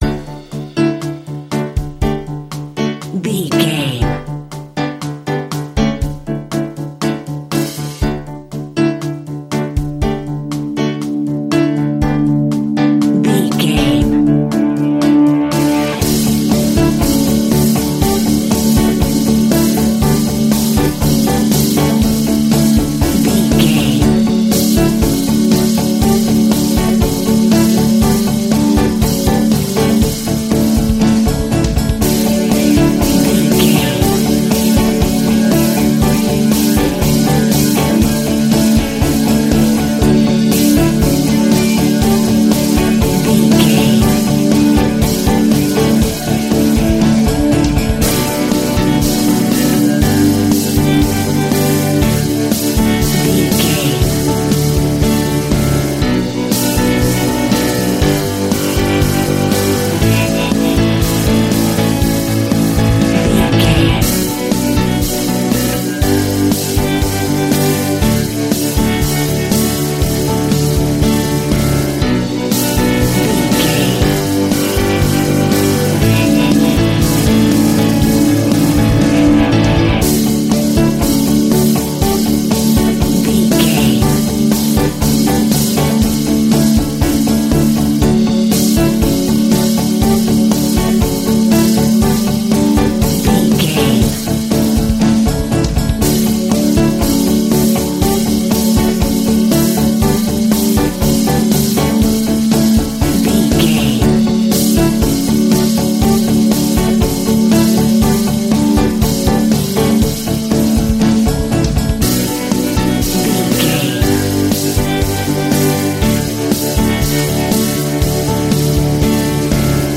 Soft Pop Rock Radio.
Ionian/Major
D
pop rock
indie pop
energetic
uplifting
instrumentals
upbeat
groovy
guitars
bass
drums
piano
organ